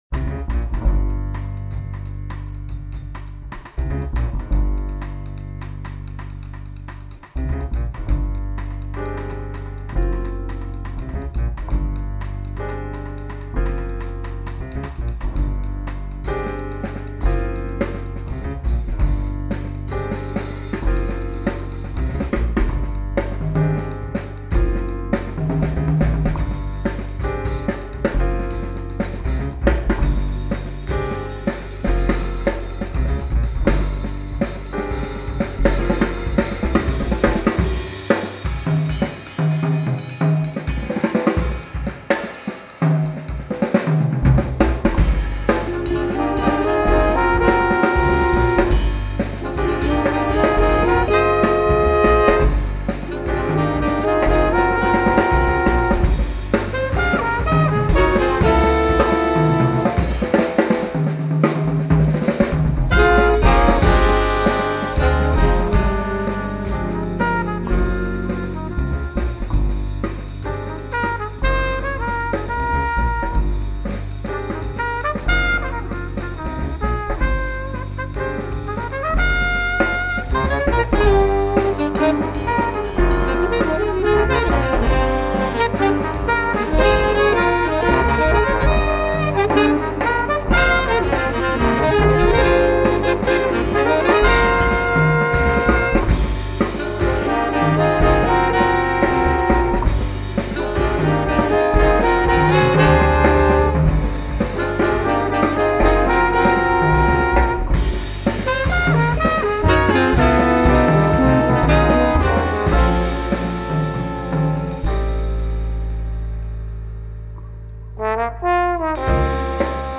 This is a big sound, but one with a world of implied space.